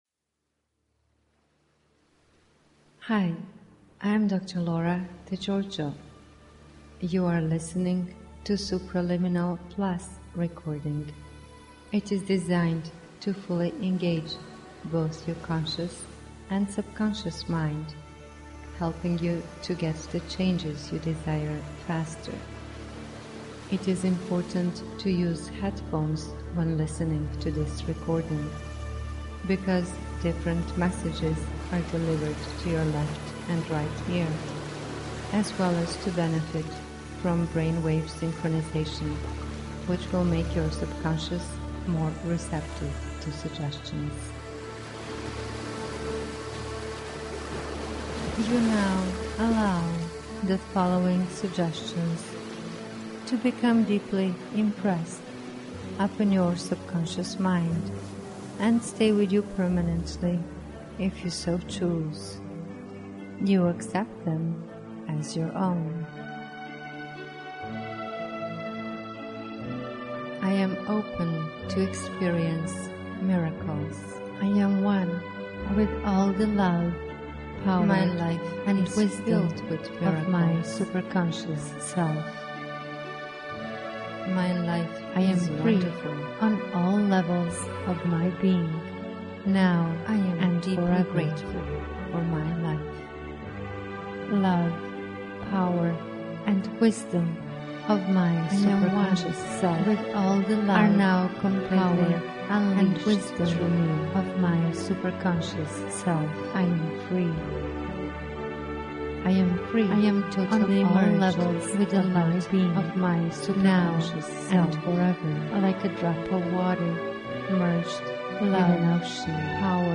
Tags: Science & Nature Hypnosis Hypnosis Recordings Hypnosis Audio Clips Therapy